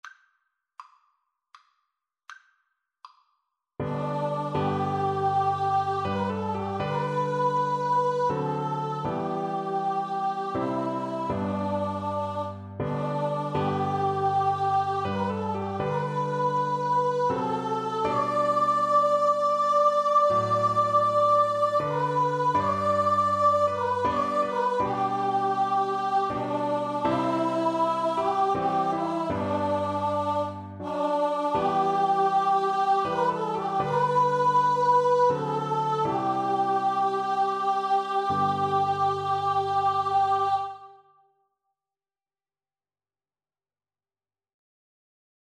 Free Sheet music for Vocal Duet
Amazing Grace is a Christian hymn with words written by the English poet and clergyman John Newton (1725–1807), published in 1779.
3/4 (View more 3/4 Music)
G major (Sounding Pitch) (View more G major Music for Vocal Duet )
Andante
Traditional (View more Traditional Vocal Duet Music)